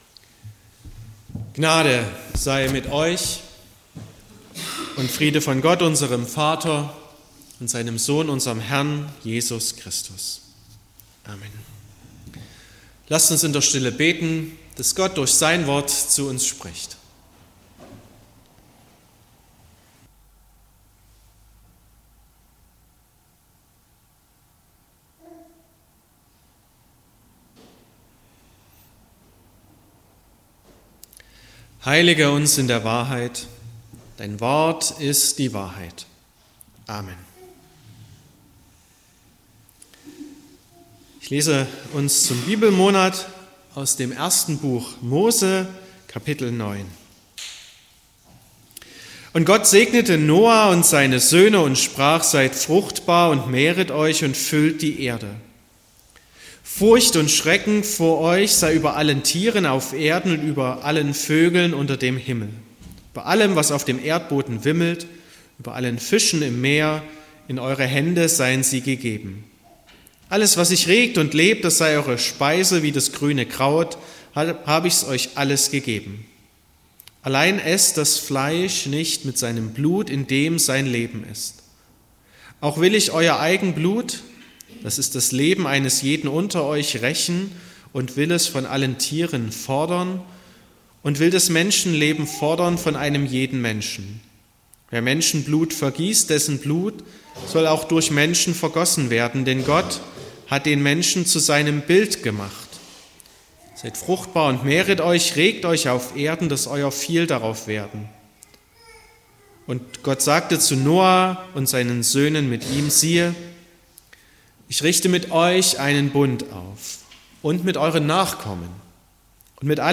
22.09.2024 – Gottesdienst
Predigt (Audio): 2024-09-22_Der_Regenbogen_steht_fuer_das_Leben__Bibelmonat_2024__Thema_7_.mp3 (15,0 MB)